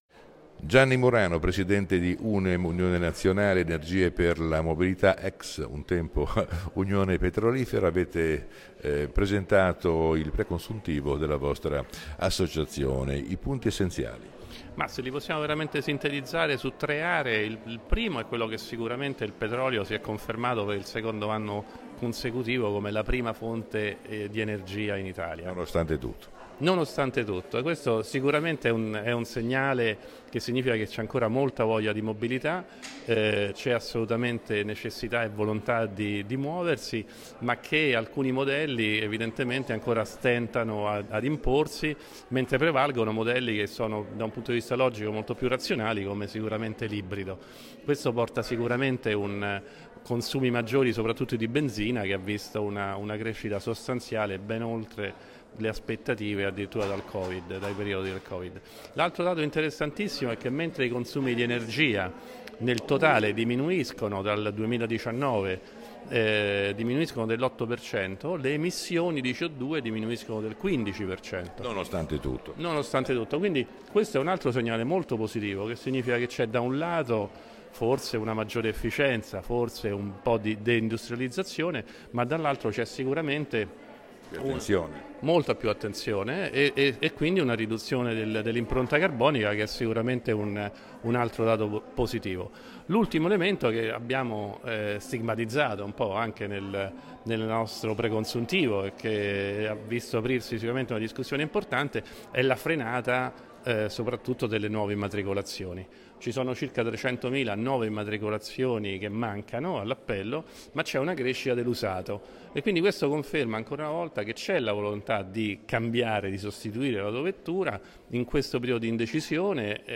L’intervista completa https